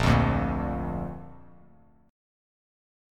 Bb Chord
Listen to Bb strummed